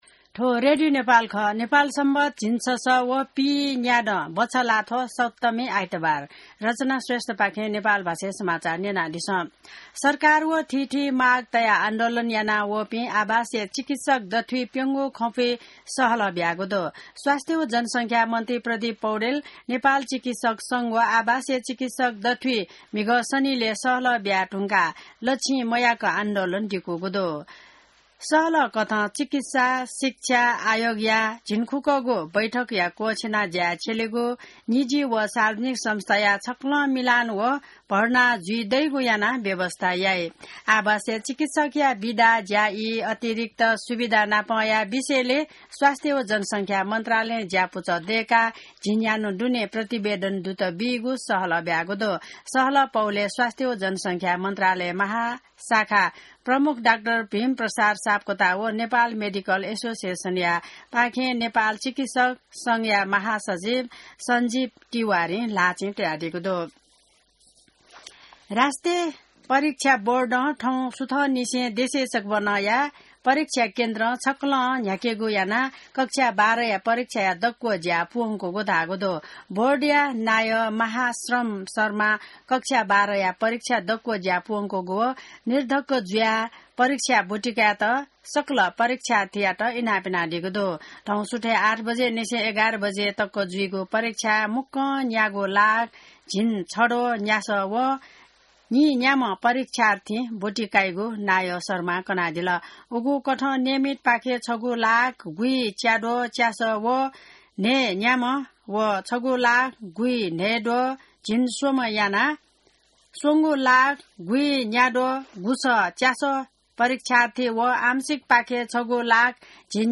नेपाल भाषामा समाचार : २१ वैशाख , २०८२